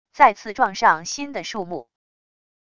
再次撞上新的树木wav音频